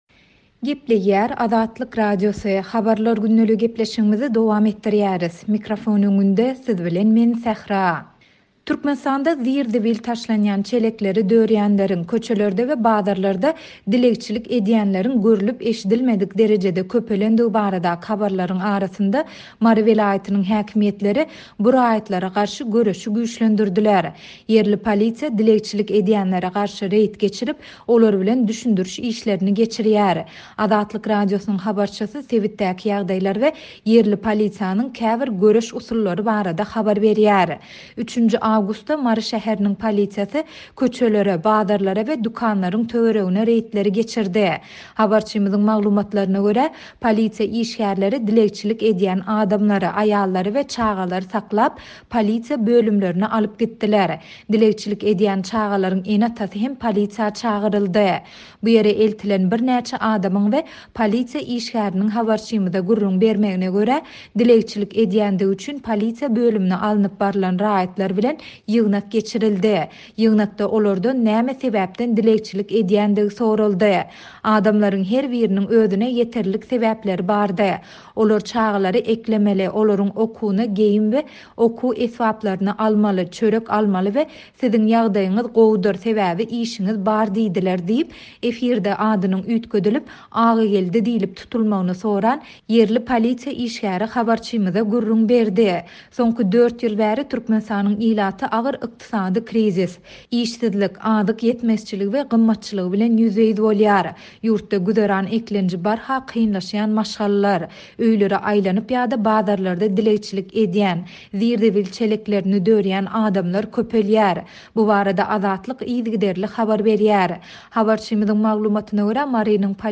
Ýerli polisiýa dilegçilik edýänlere garşy reýd geçirip, olar bilen "düşündiriş işlerini" geçirýär. Azatlyk Radiosynyň habarçysy sebitdäki ýagdaýlar we ýerli polisiýanyň käbir göreş usullary barada habar berýär.